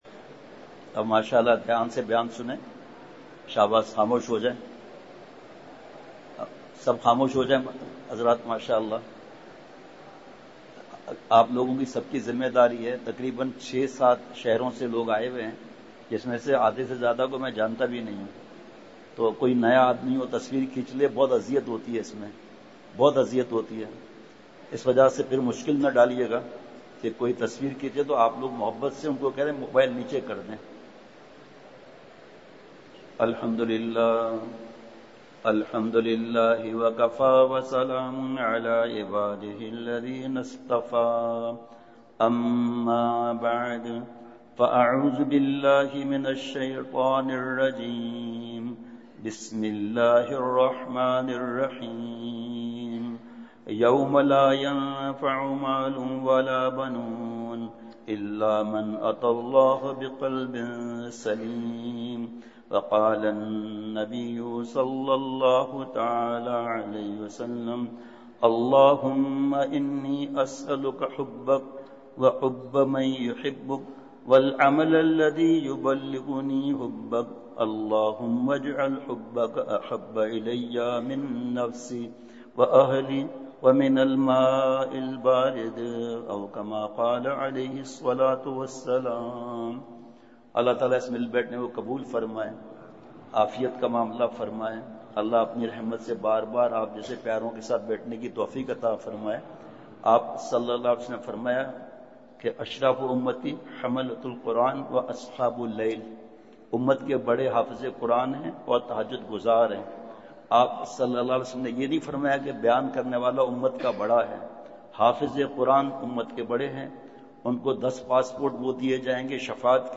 *بمقام: جامعہ خیرالمدارس ملتان*